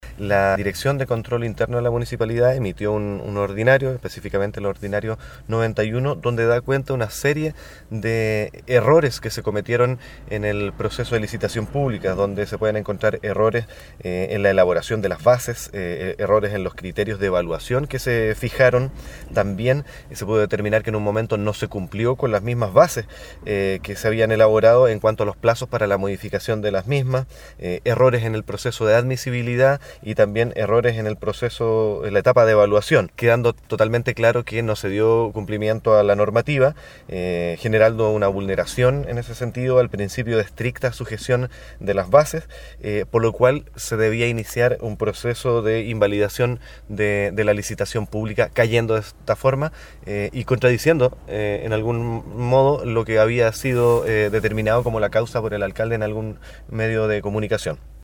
El concejal Rodolfo Norambuena, que al momento de la votación optó por la abstención, enumeró las falencias que registró dicha licitación.